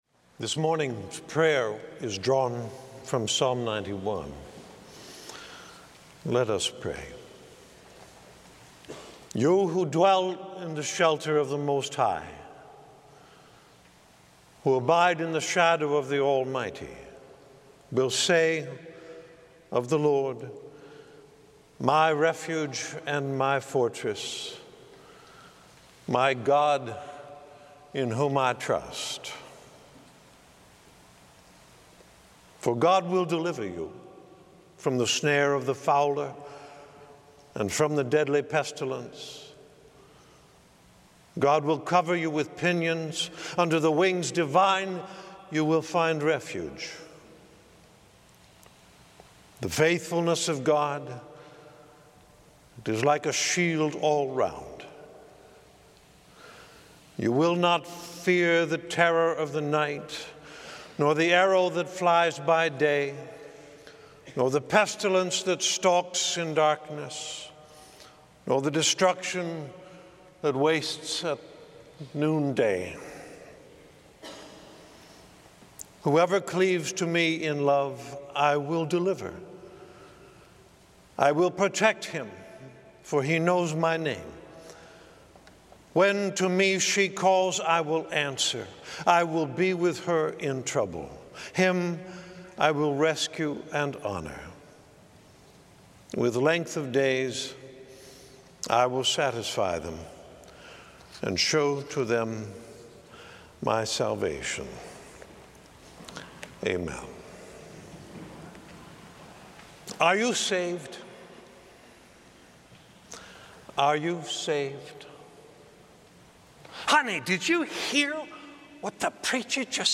sermon 2013